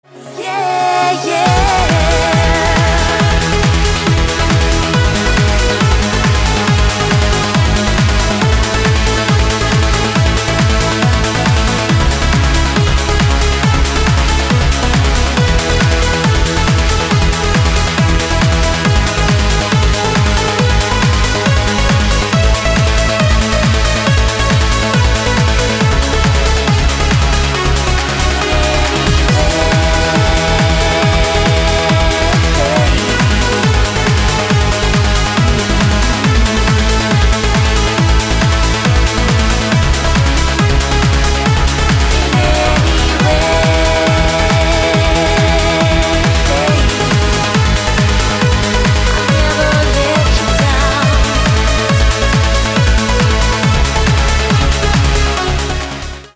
• Качество: 256, Stereo
громкие
женский вокал
dance
Electronic
EDM
электронная музыка
Trance